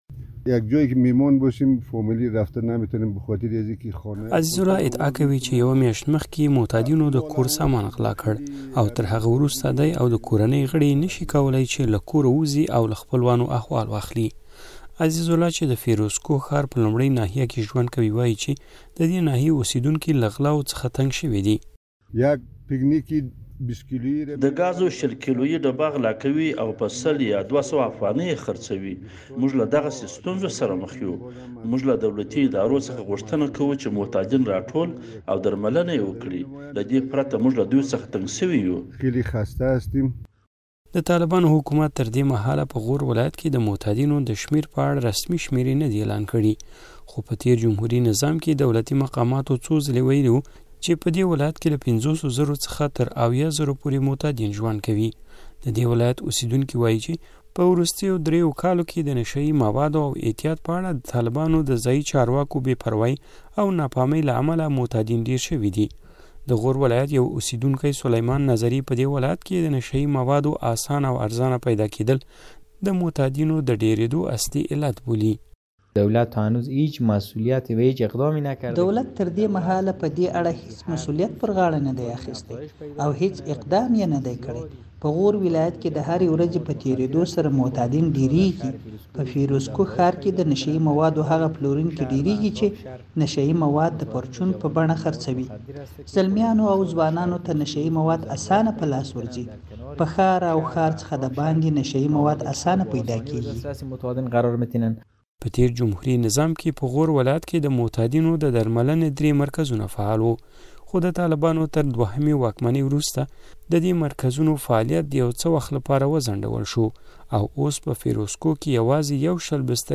د غور راپور